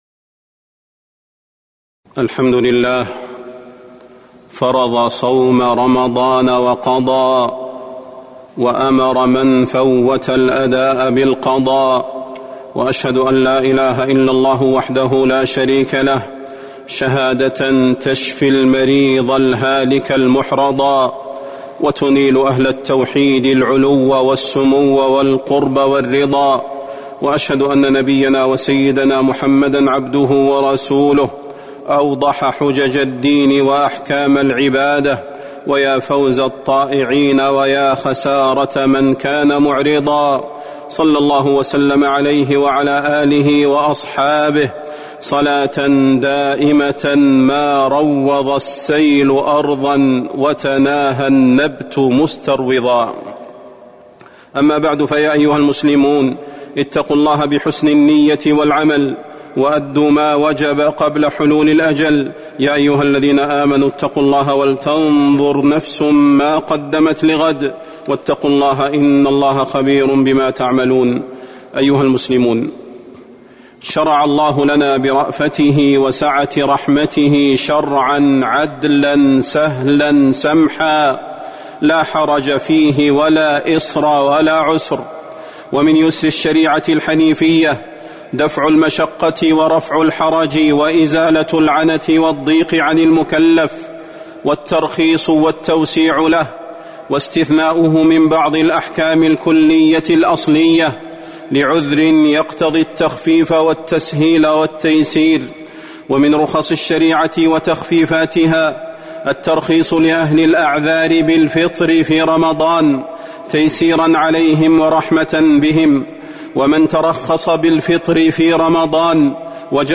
تاريخ النشر ٨ شعبان ١٤٤٣ هـ المكان: المسجد النبوي الشيخ: فضيلة الشيخ د. صلاح بن محمد البدير فضيلة الشيخ د. صلاح بن محمد البدير أحكام صوم القضاء